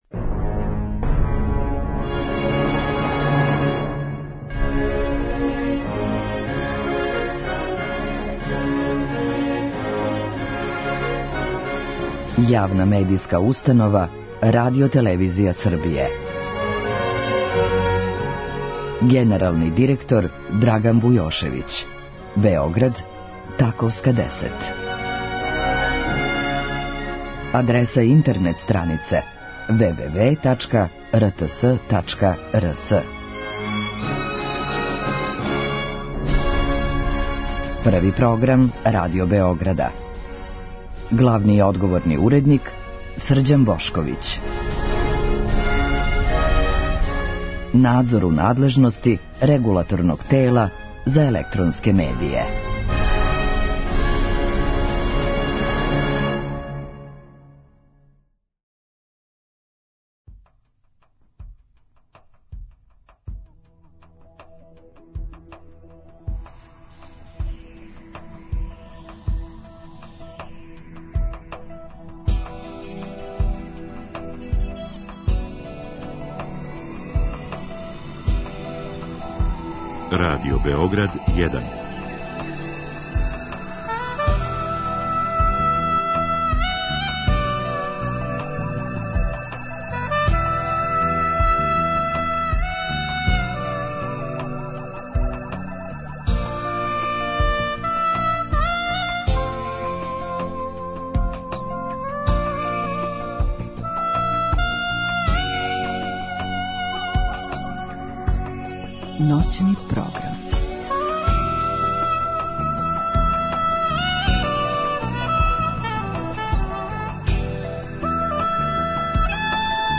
Уз најбоље жеље за новогодишње и божићне празнике, у Ноћном програму ове суботе одабрали смо најлепше музичке нумере из емисија које сте имали прилику да слушате у претходној години.